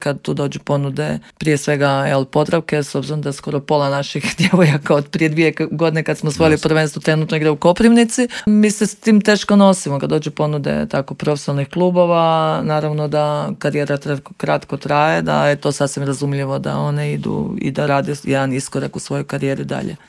O pripremama za ove dvije dvije važne utakmice sa švicarskim Amicitom iz Zuricha govorila je u Intervjuu Media servisa direktorica kluba i naša legendarna bivša rukometašica Klaudija Bubalo (Klikovac).